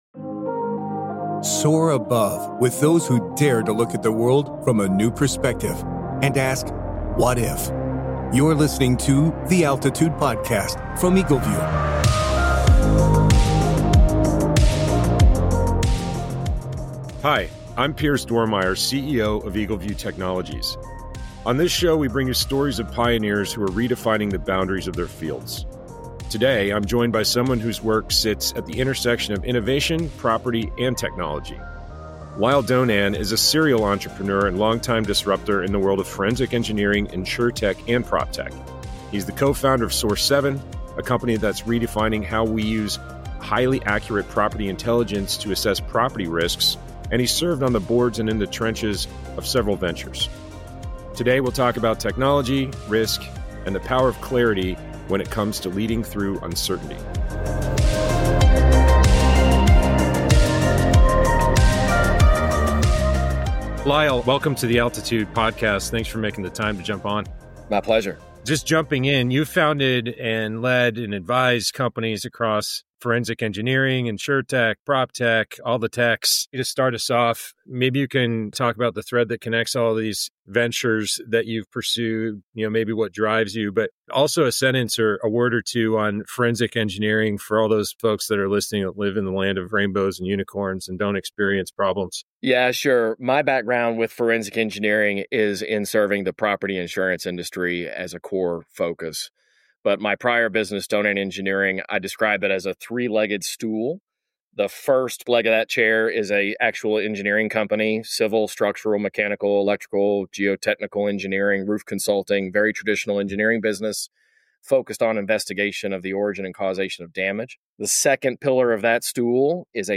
We sit down with the trailblazers and thinkers – those who dare to look at the world from a new perspective and ask, "What if?"